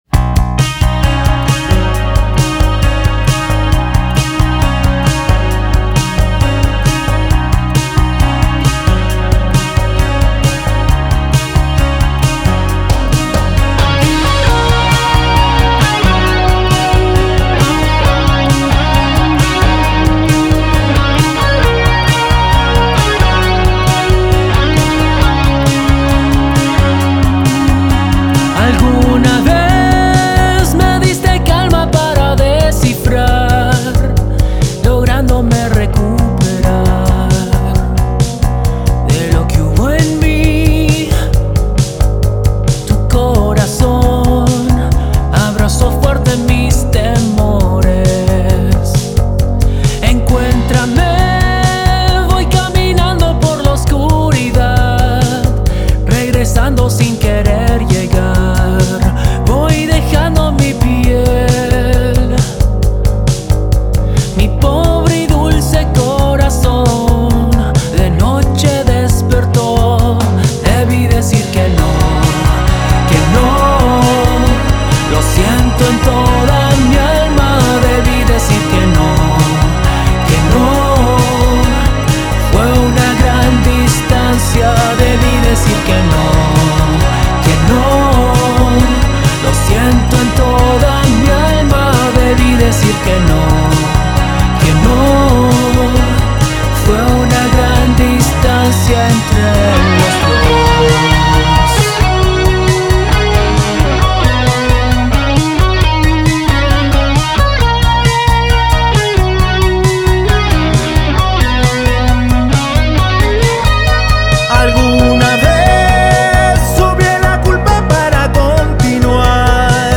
Productor Musical, Compositor, Guitarrista y Sintetizadores